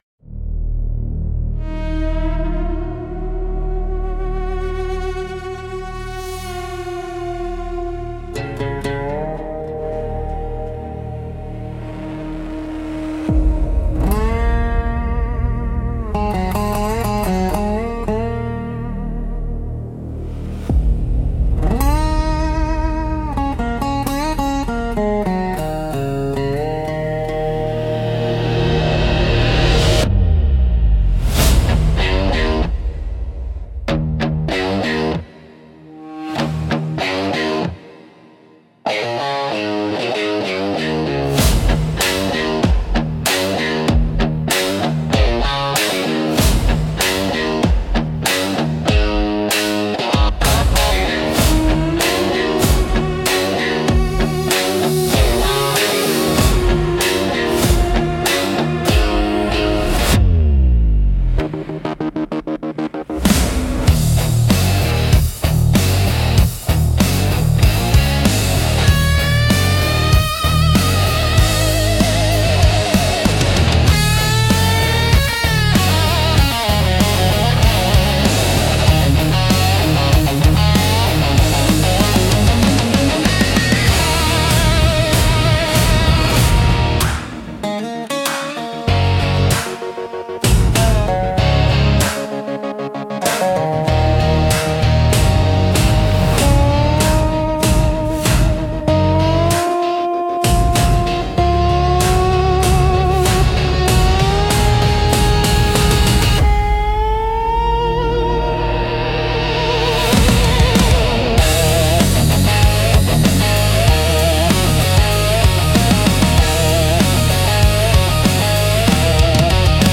Cinematic Western Hybrid